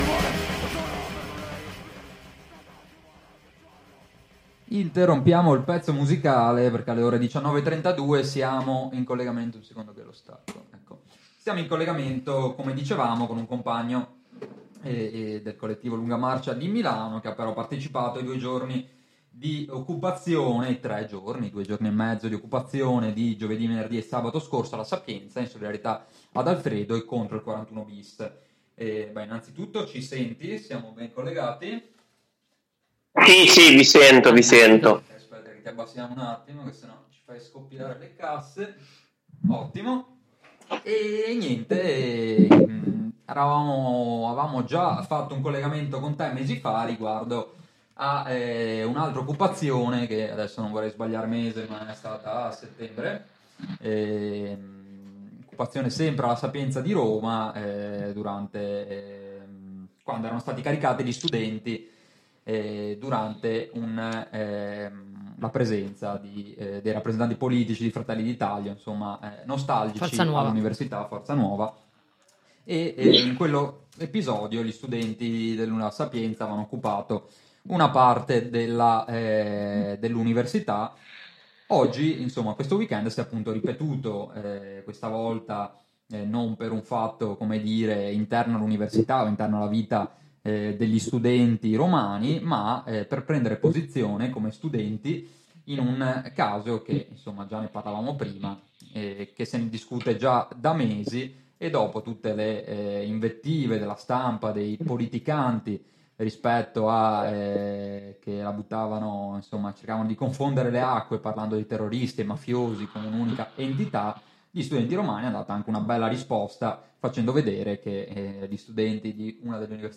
Ne parliamo con un compagno del collettivo Lunga Marcia presente ai due giorni di occupazione. ControVento – pt.44